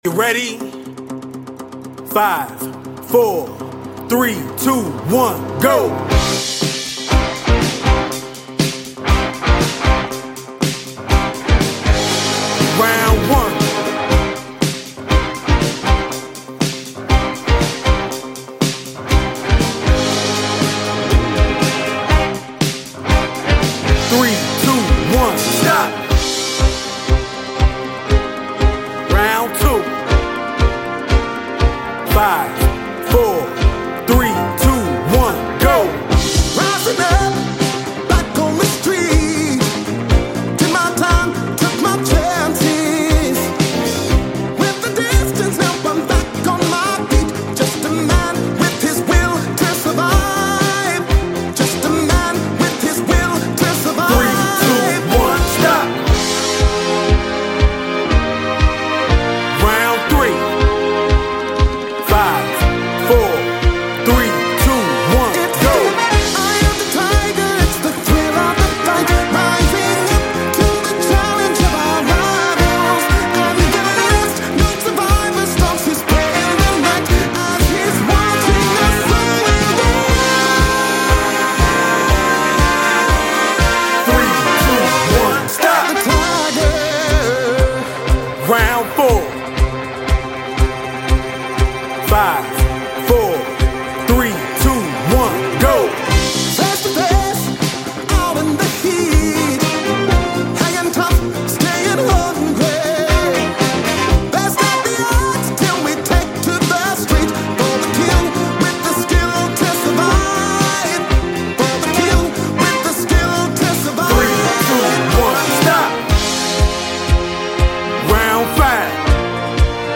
Audi q7 3.0 tdi engine sound effects free download
Audi q7 3.0 tdi engine noise!